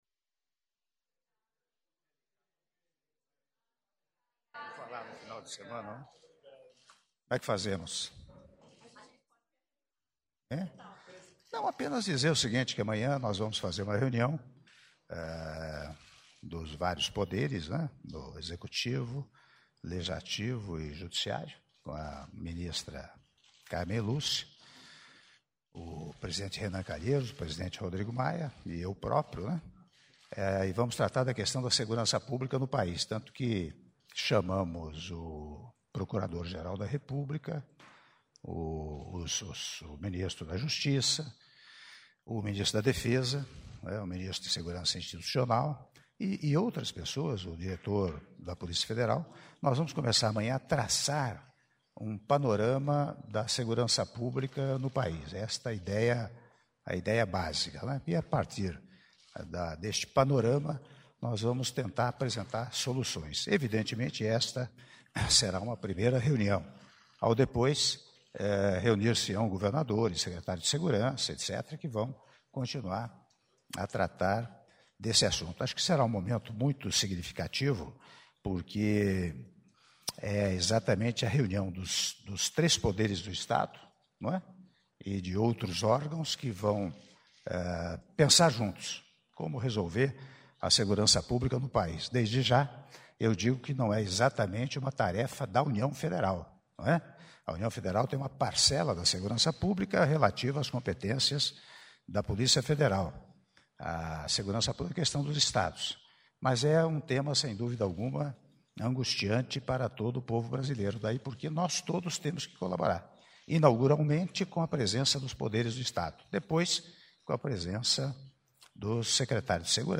Áudio da entrevista coletiva concedida pelo presidente da República, Michel Temer, após cerimônia de Apresentação de Credenciais de Embaixadores (4min47s) - Brasília/DF